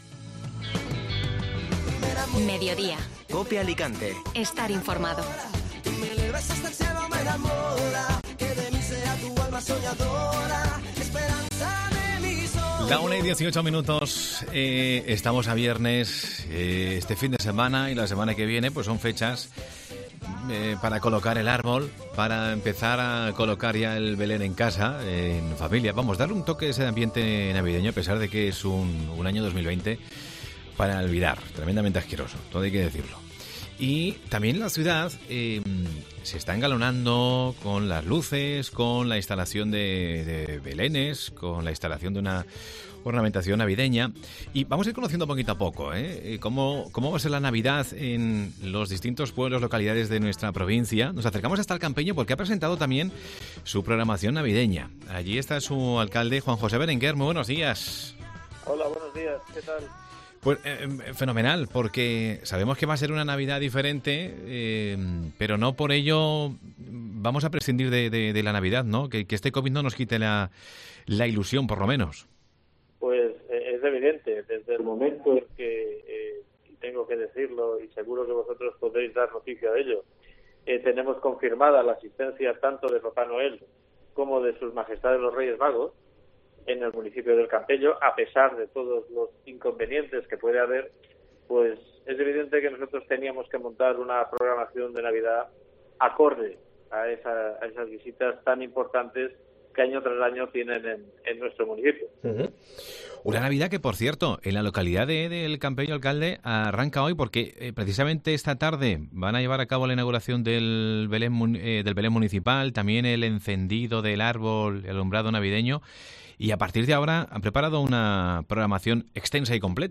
Juan José Berenguer, alcalde de El Campello presenta la programación navideña